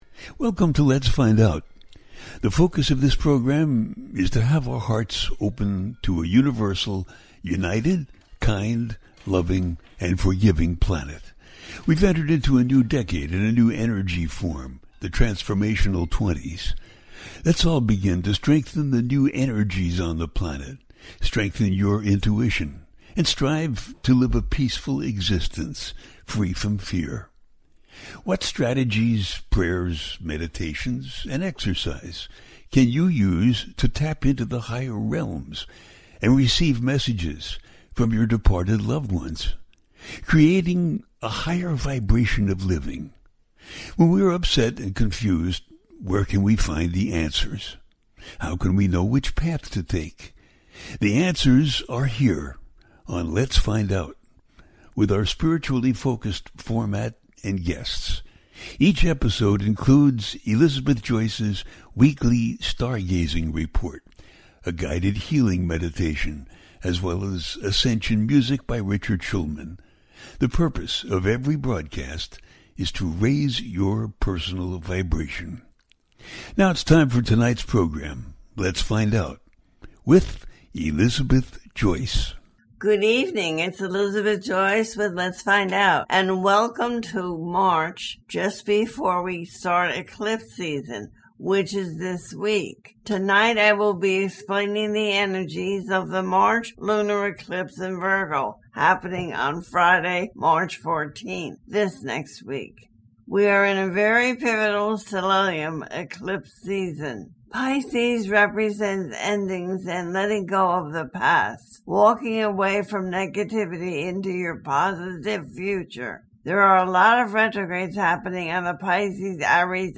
The March Virgo Lunar Eclipse 2025 - A teaching show